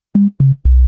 exclamation.ogg